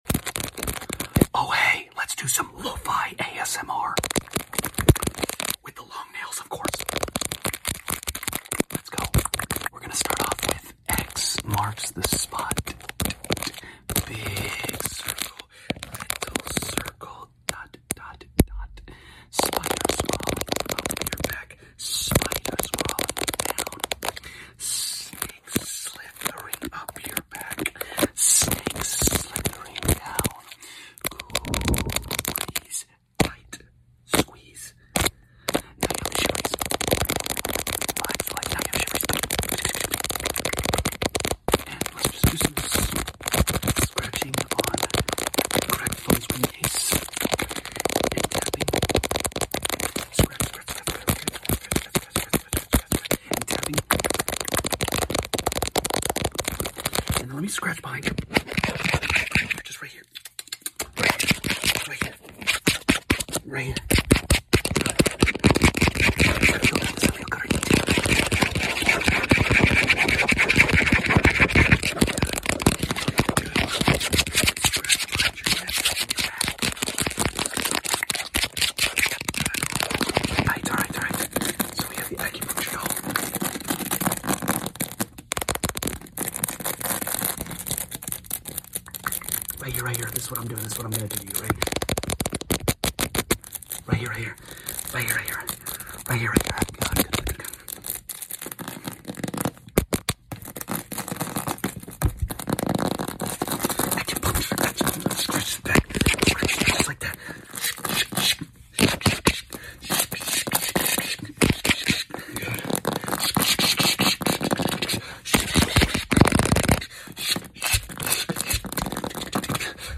LONG NAILS LOFI ASMR 💅🎧 sound effects free download
(Taps & Relaxing Scratches)